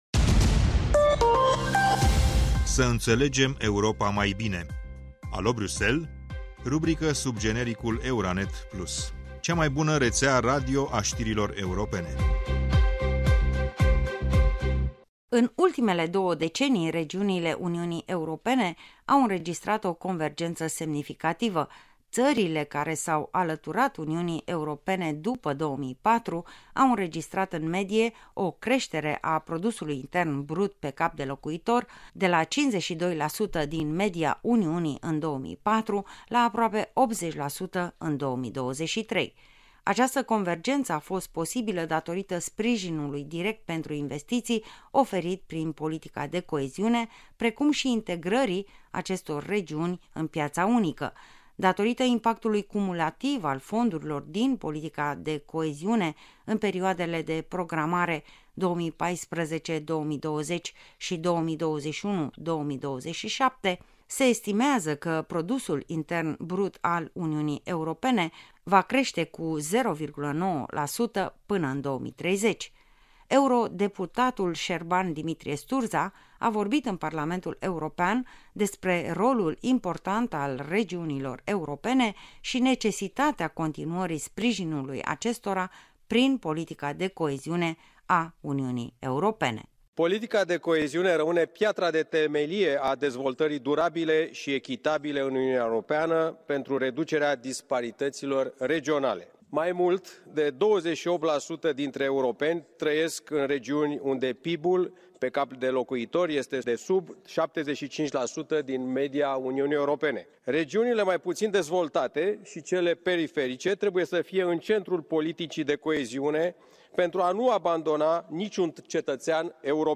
Eurodeputatul Șerban-Dimitrie Sturdza a vorbit în Parlamentul European despre rolul important al regiunilor europene și necesitatea continuării sprijinului acestora prin politica de coeziune a Uniunii Europene.